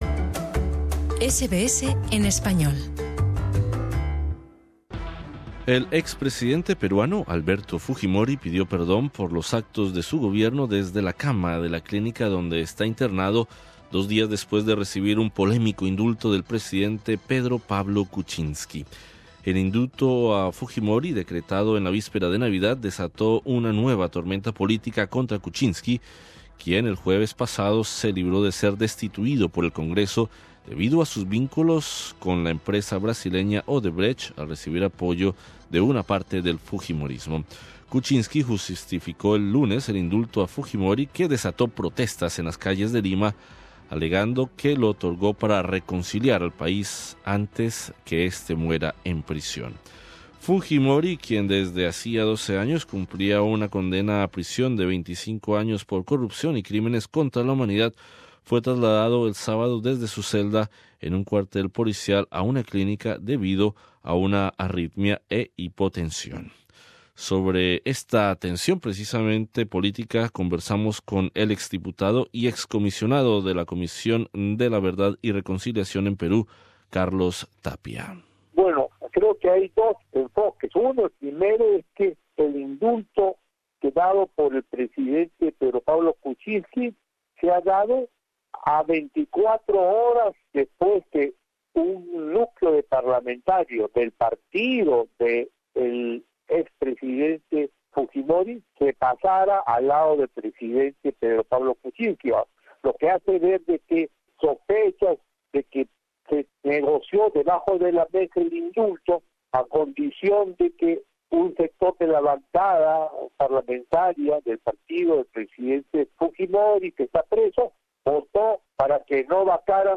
Sobre esta tensión política conversamos con el ex diputado y ex Comisionado de la Comisión de la Verdad y Reconciliación en Perú, Carlos Tapia.